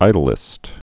(īdl-ĭst)